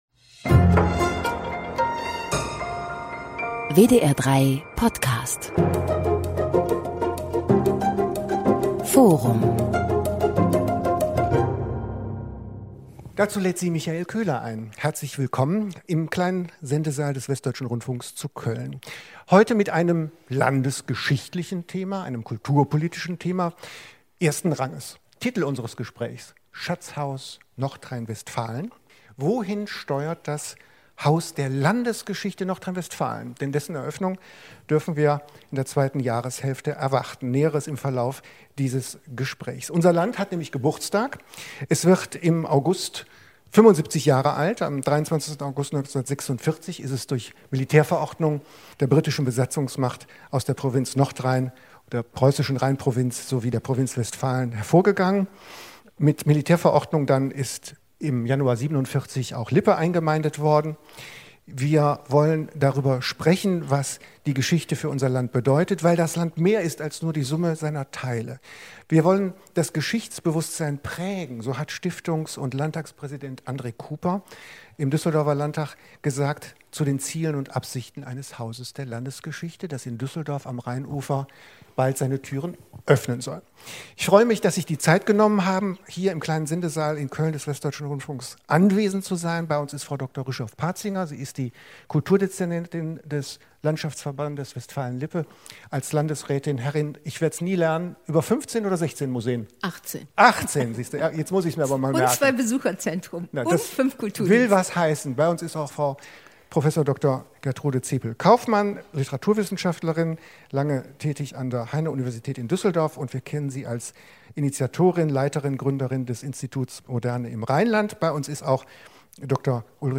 Aufzeichnung einer Diskussion aus dem Kleinen Sendesaal des WDR in Köln am 23.02.2021